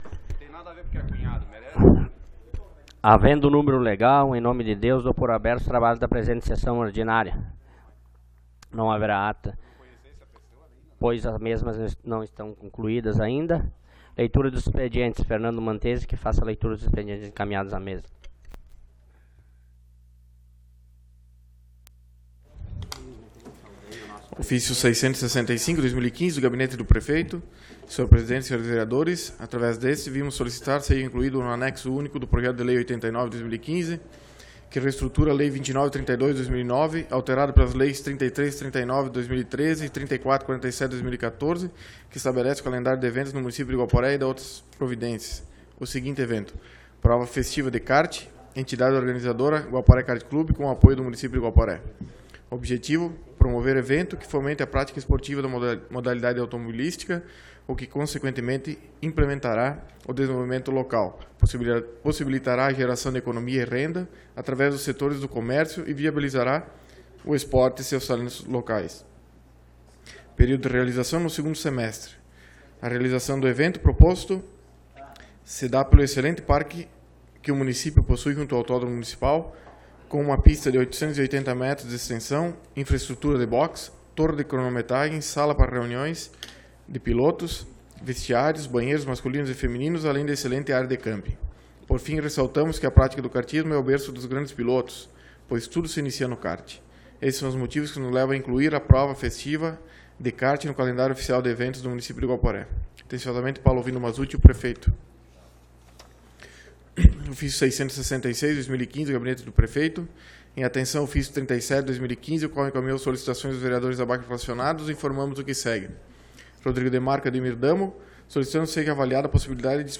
Sessão Ordinária do dia 24 de Dezembro de 2015